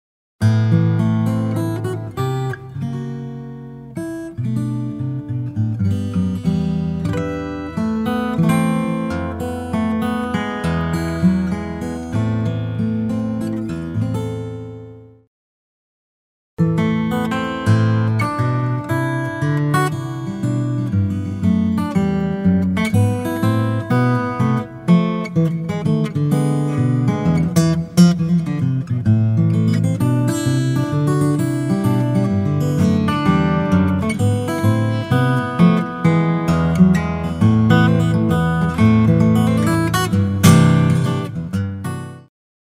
multi-style arrangement